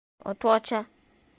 En-Otuocha.ogg.mp3